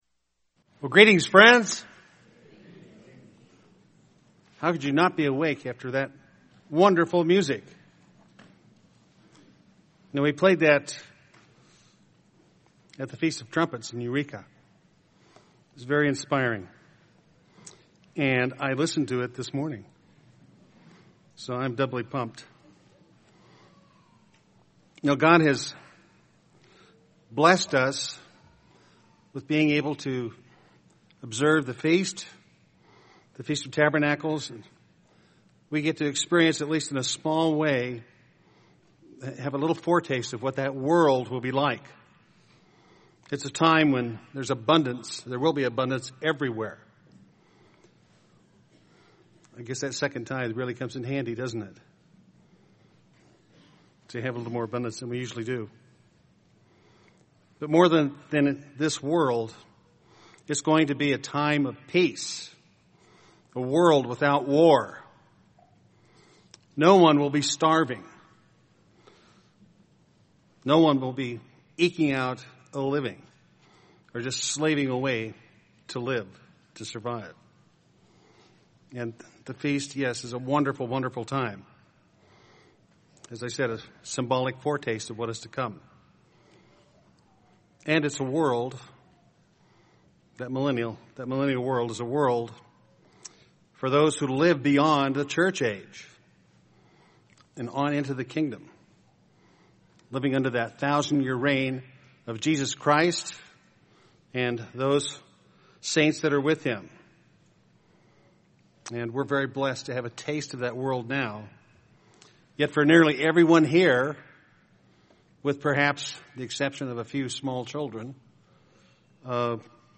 This sermon was given at the Bend, Oregon 2013 Feast site.